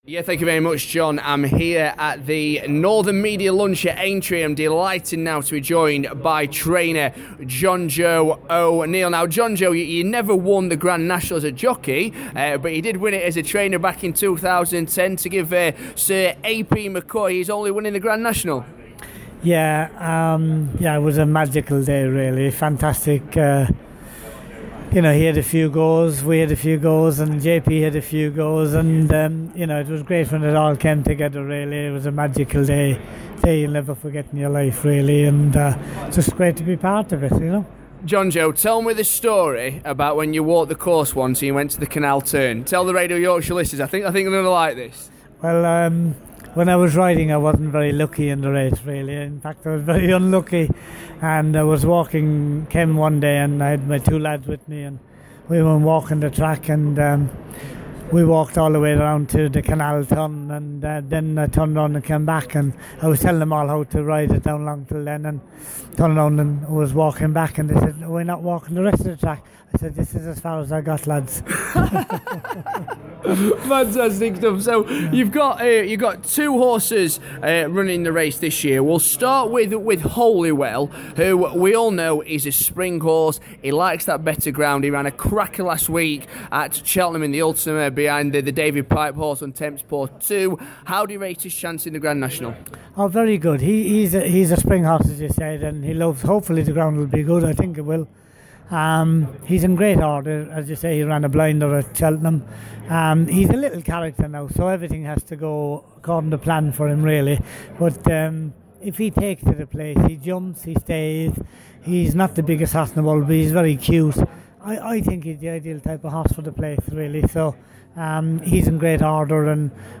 at the official Aintree lunch with the big names in racing